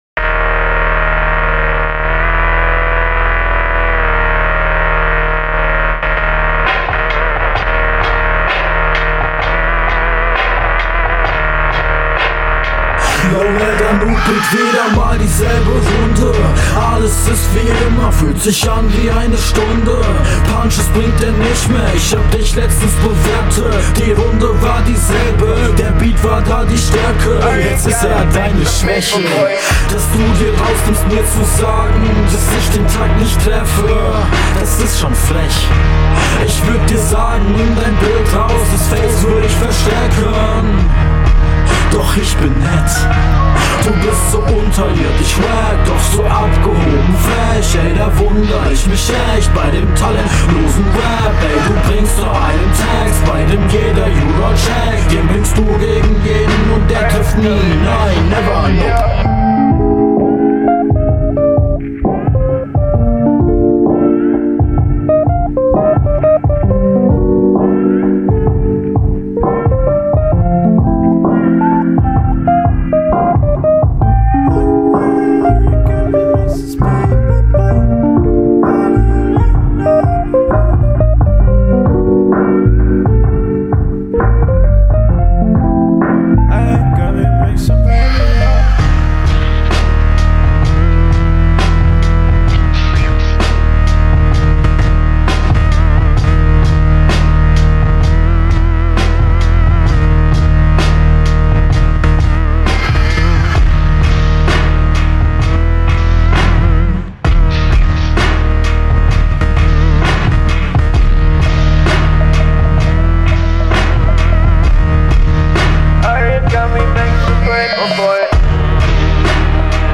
Ah okay, da hat jmd den Beat nicht gecutted!